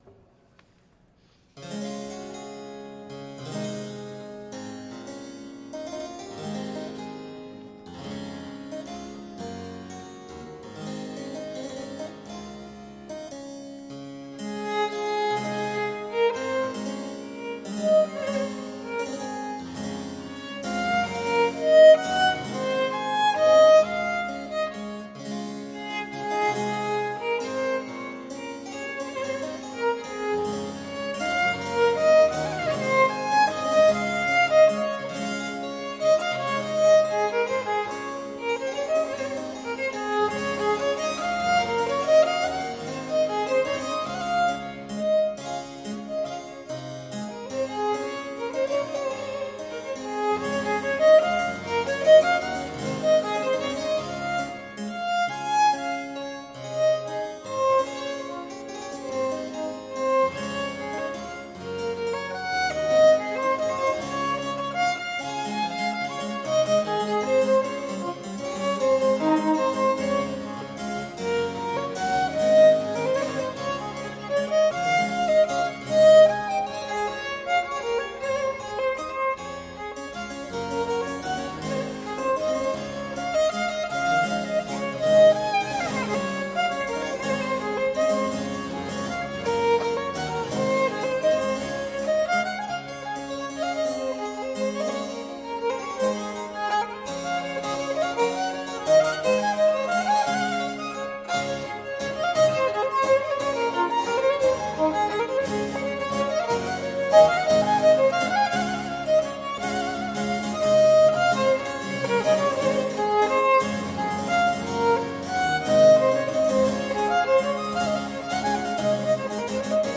St Vedast Foster Lane